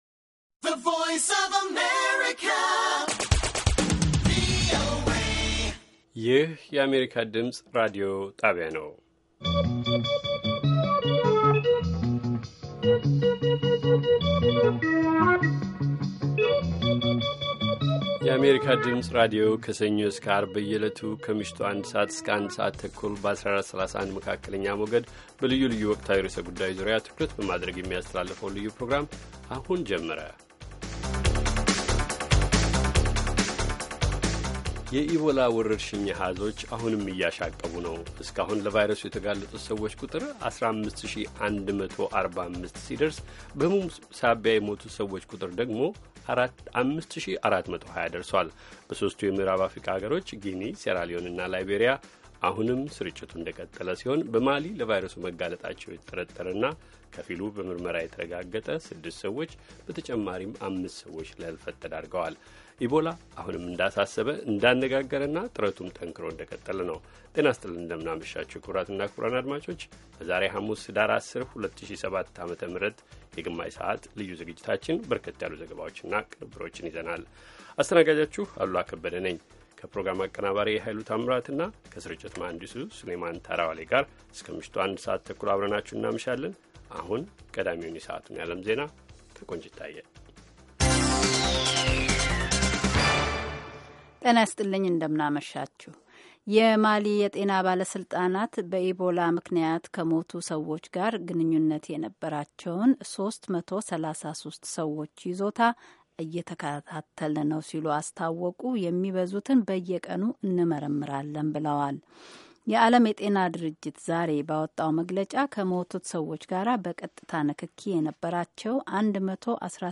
ከምሽቱ ኣንድ ሰዓት የአማርኛ ዜና